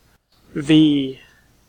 Ääntäminen
Vaihtoehtoiset kirjoitusmuodot V. Synonyymit fifth Ääntäminen : IPA : /ˈvi/ Tuntematon aksentti: IPA : /ˈviː/ Haettu sana löytyi näillä lähdekielillä: englanti V on sanan vocative case lyhenne (kielioppi).